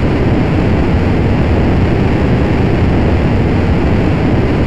sfx
rocket.ogg